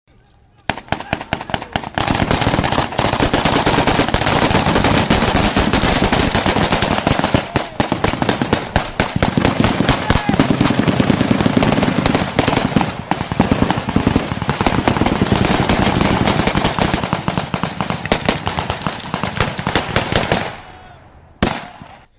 Интенсивный стрелковый бой
Отличного качества, без посторонних шумов.
113_gunfight.mp3